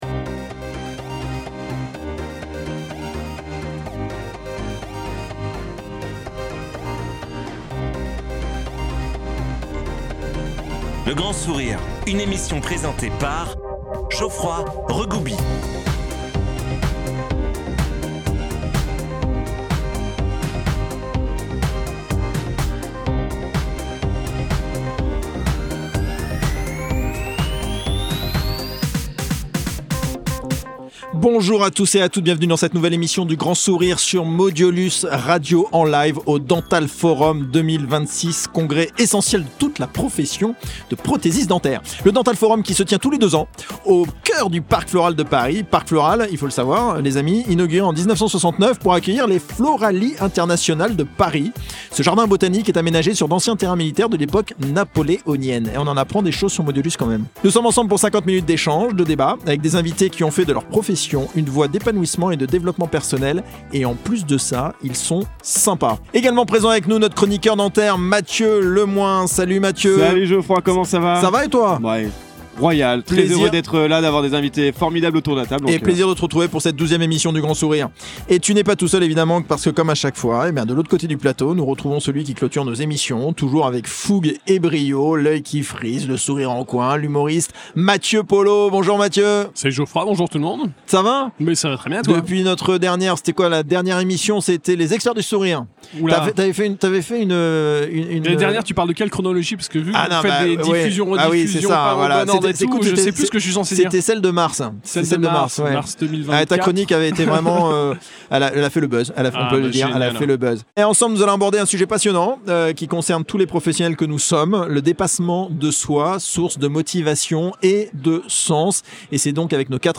L'épisode, enregistré en direct du Dental Forum 2026, explore le thème du dépassement de soi dans les carrières professionnelles, en particulier dans le domaine de l'artisanat et de la prothèse dentaire.